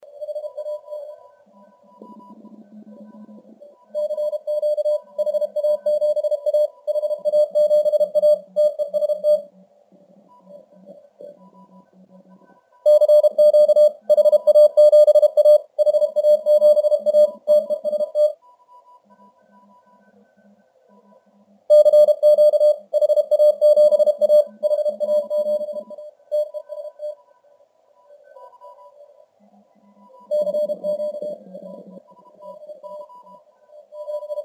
Запись велась: в CW 200Hz, в SSB 2,4kHz.
Продолжаю выкладывать записи работы приемника (tr)uSDX-a. Запись делалась во время проведения IARU HF Championship.